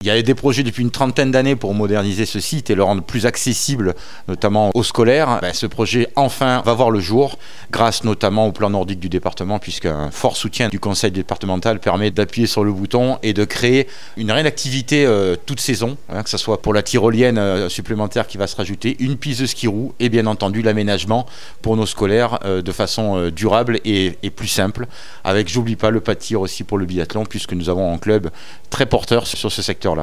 Jean-Philippe Mas, président de la Communauté de communes Cluses Arve et Montagnes et vice-président du conseil départemental, revient sur les aménagements.